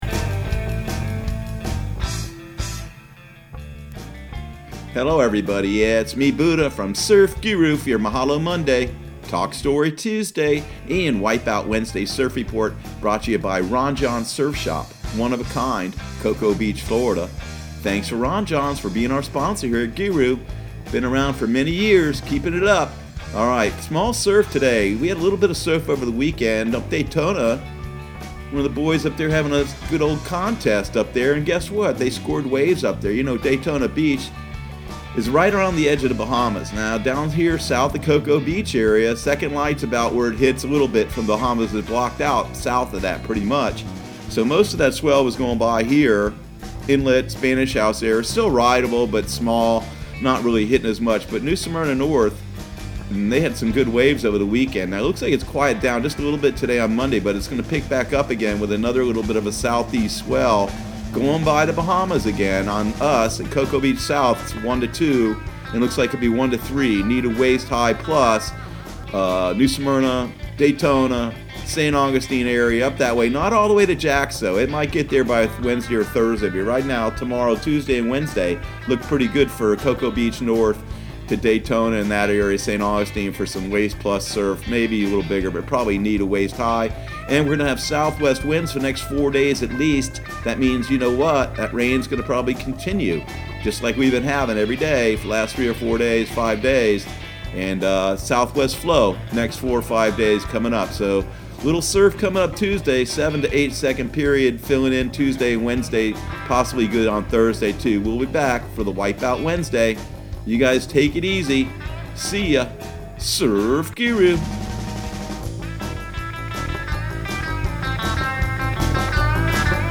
Surf Guru Surf Report and Forecast 08/05/2019 Audio surf report and surf forecast on August 05 for Central Florida and the Southeast.